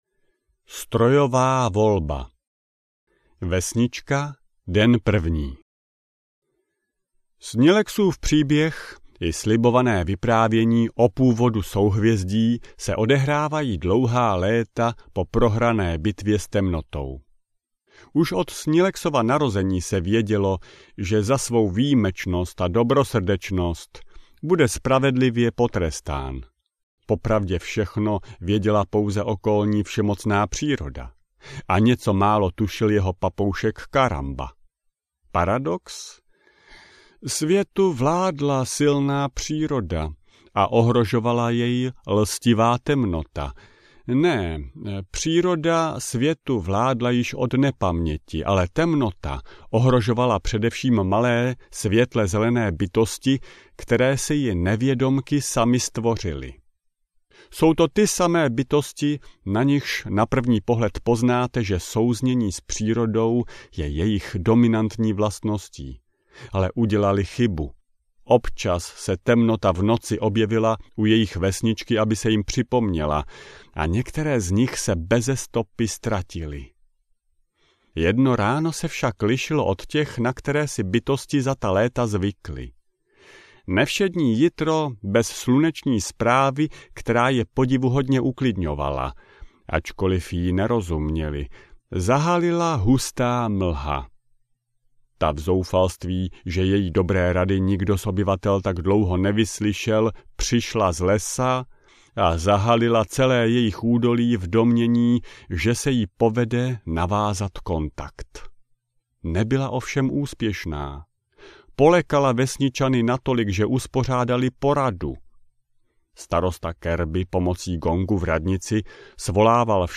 Sñilex a Derik audiokniha
Ukázka z knihy